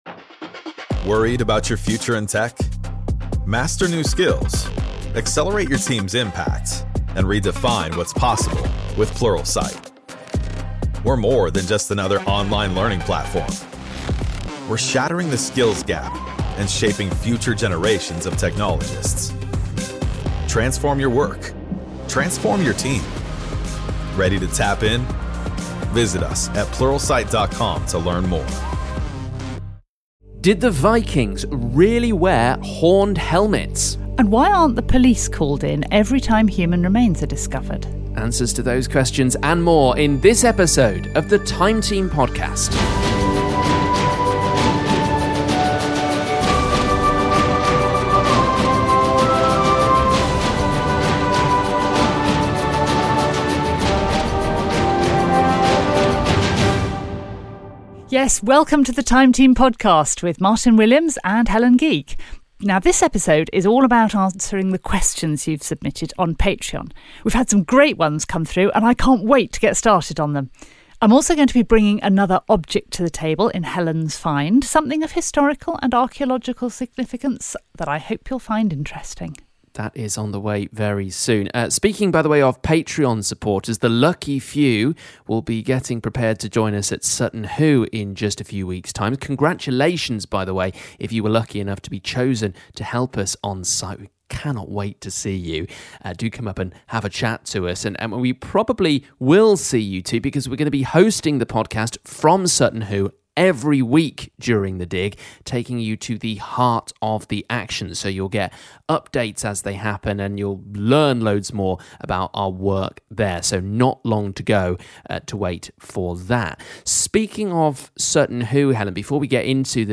Archaeologist Dr Helen Geake answers more of your questions in this episode of the Time Team podcast. From whether Vikings really wore horned helmets to why the police aren't called in every time a skeleton is discovered at a site.